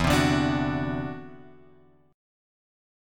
E7sus2#5 chord {0 3 0 x 3 2} chord